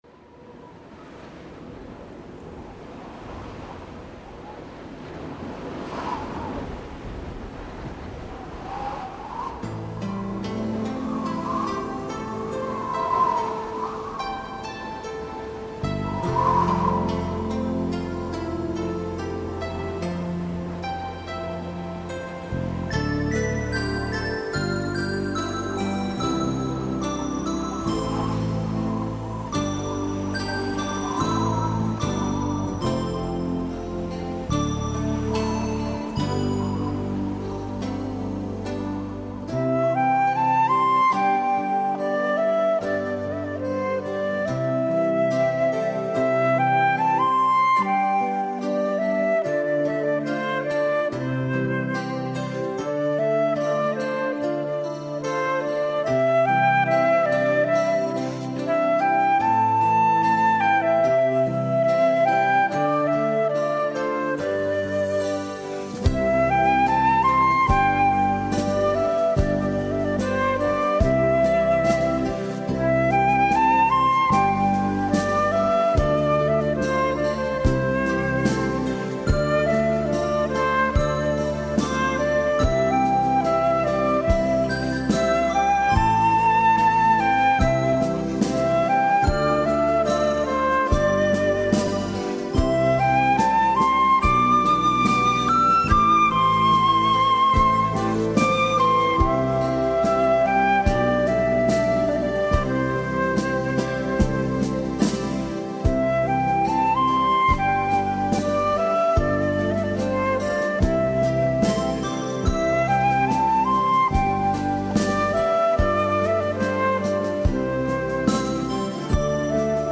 音乐类型：纯音乐